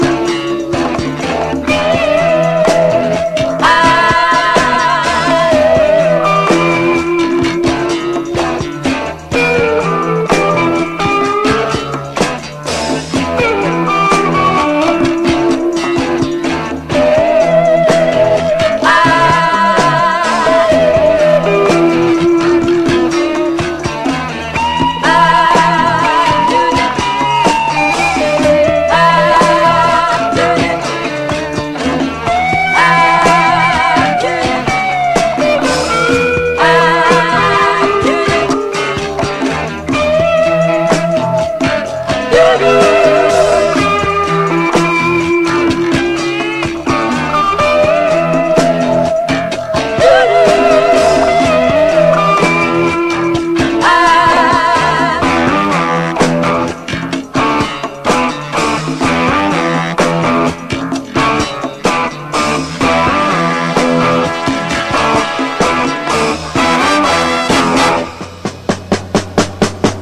JAPANESE SOFT ROCK
見事なオーケストレイションは感動せずにいられません！